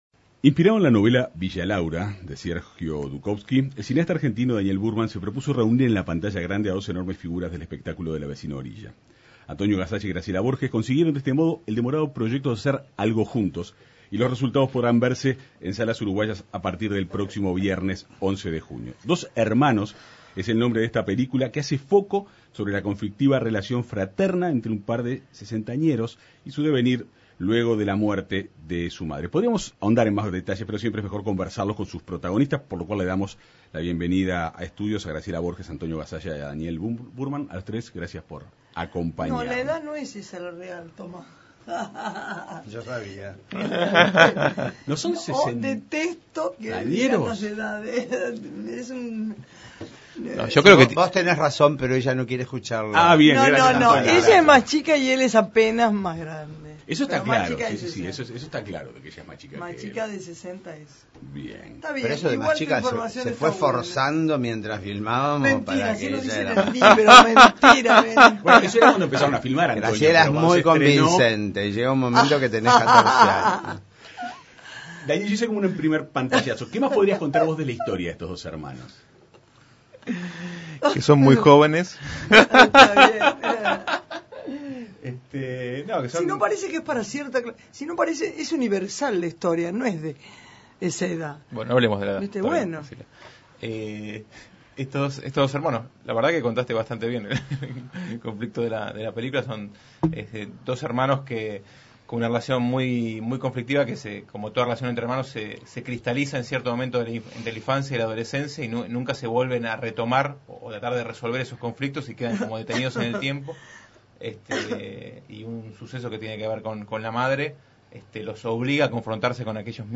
El director y los actores conversaron en la Segunda Mañana de En Perspectiva.